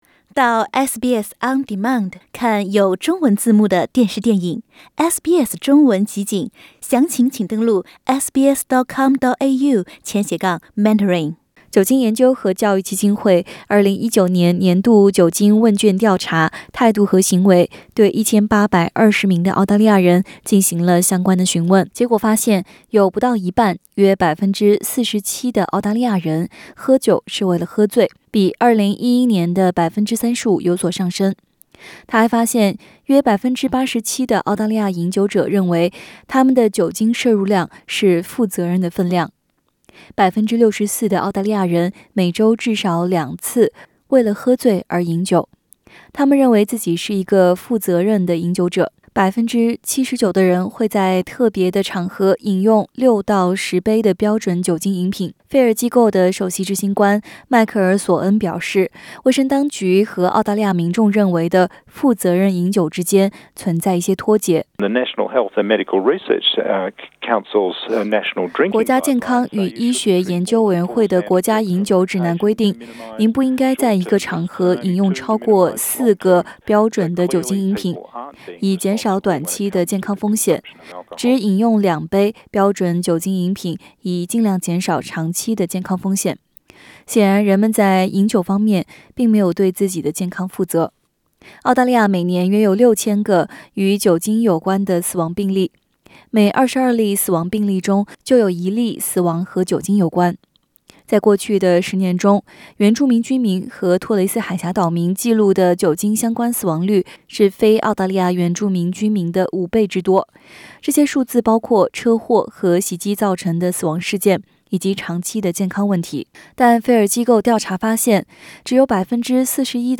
SBS 普通話電台